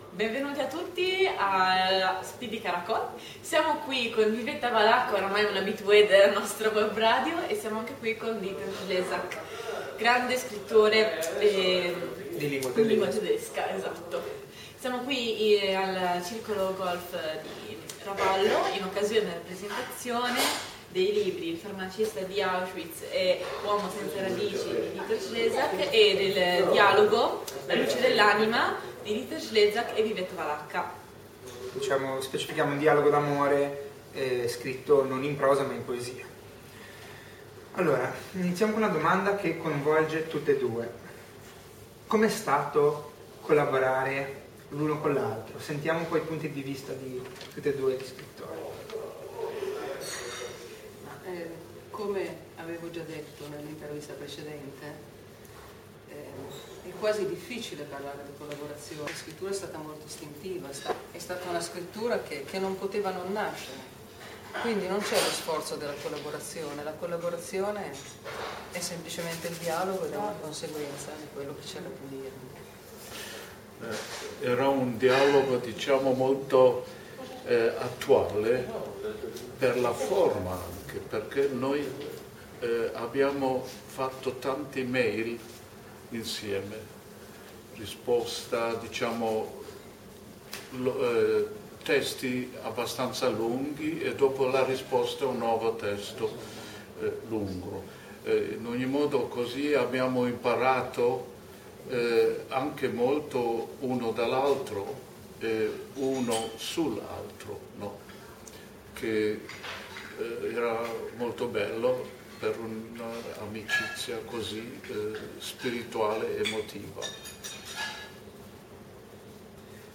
Intervista a Dieter Schlesak (poeta)